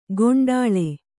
♪ goṇḍāḷe